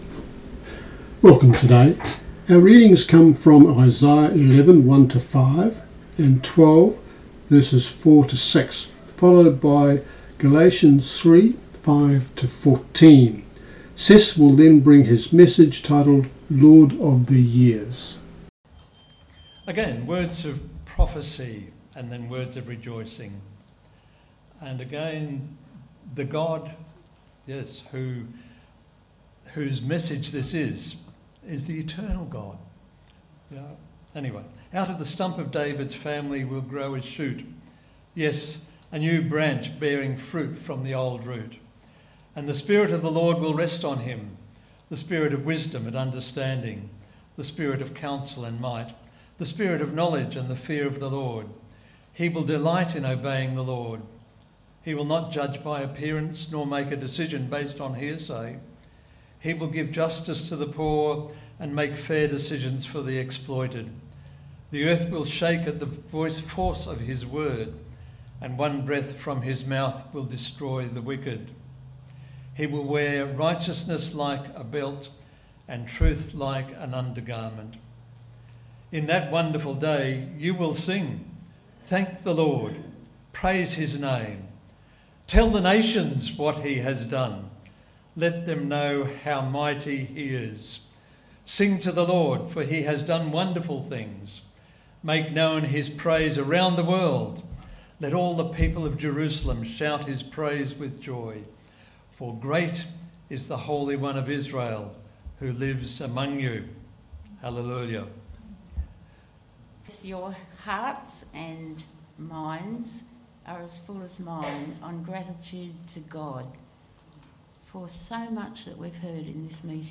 Sorry for some of the levels.